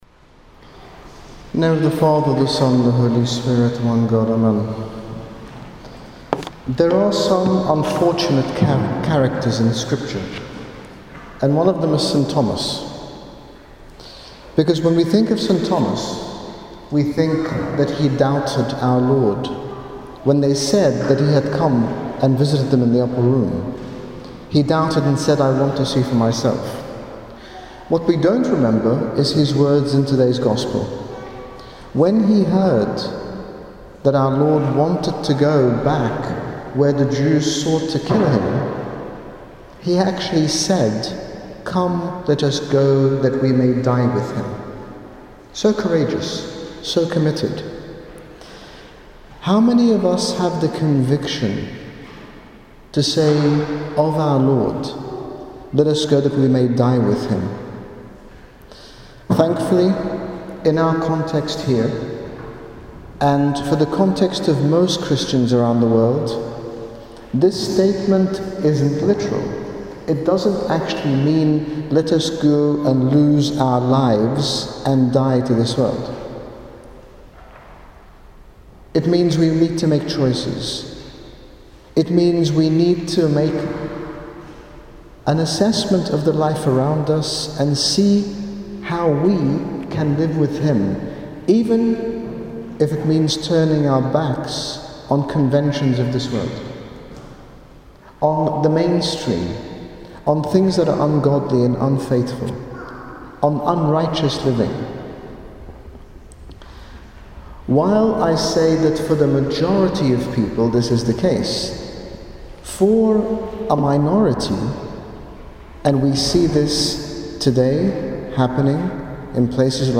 In this short sermon His Grace Bishop Angaelos, General Bishop of the Coptic Orthodox Church, talks about Saint Thomas and uses him as an example of how doubt does not necessarily mean a lack of Faith, but can actually lead to conviction.